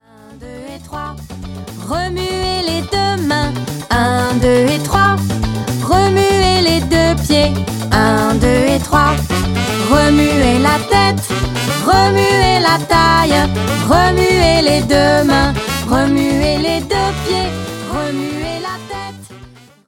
This high-energy, participatory song